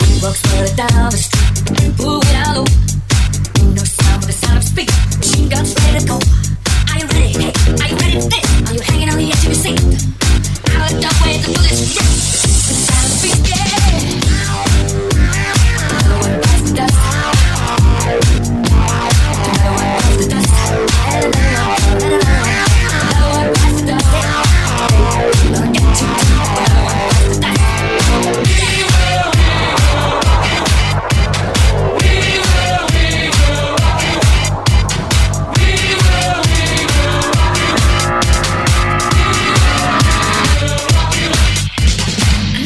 mashup 4
successi mondiali in versione mashup